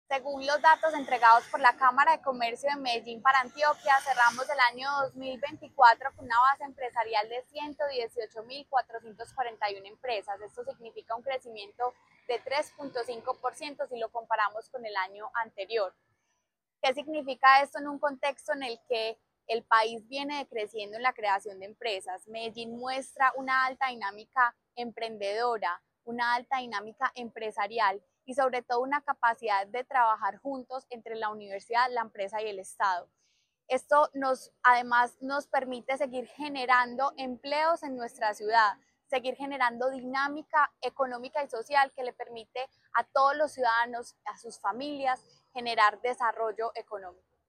Palabras de María Fernanda Galeano Rojo, secretaria de Desarrollo Económico Al cierre de 2024, Medellín reportó 118.441 empresas activas, lo que representó un incremento de 3.5 % con respecto al año 2023.